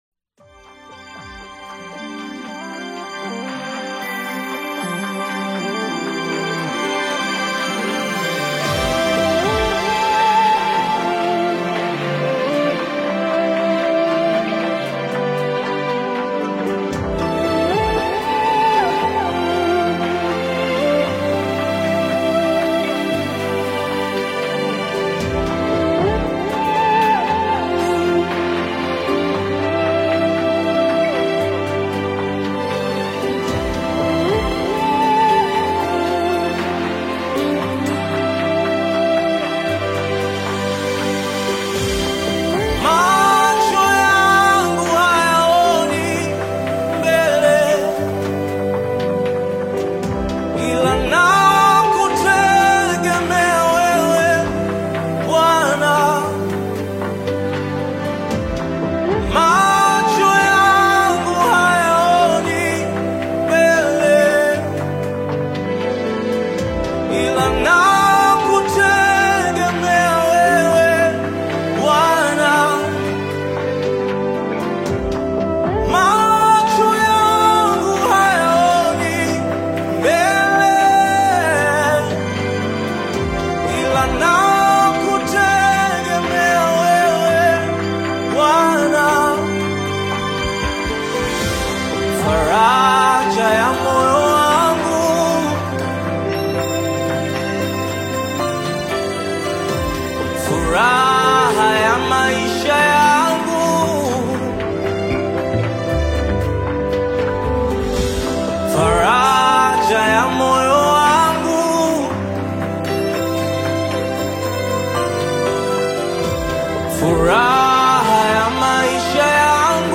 uplifting Tanzanian gospel/afro-inspired single
soulful vocalist
rich rhythmic production
Gospel